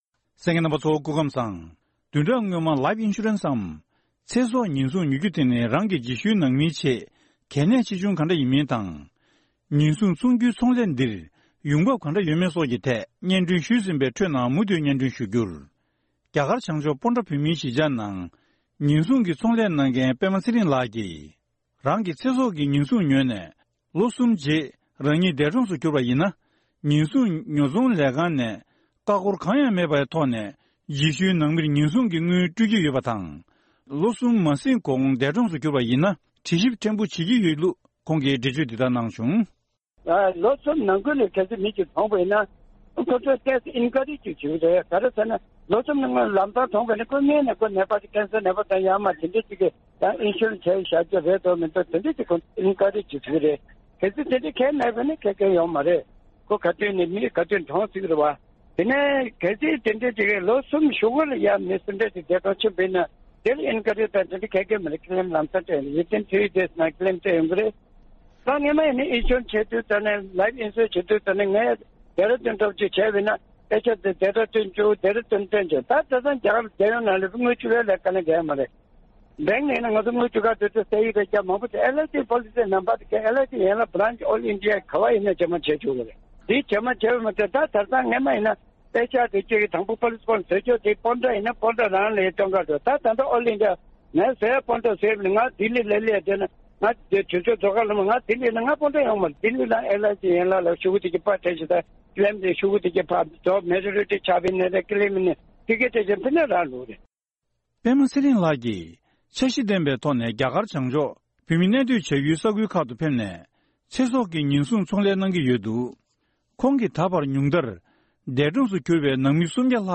བཀའ་འདྲི་ཞུས་ནས་ ཕྱོགས་སྒྲིགས་ཞུས་པ་ཞིག་གསན་རོགས་གནང་།